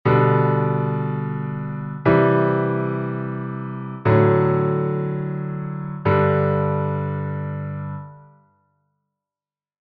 Sol M, I-IV-V-I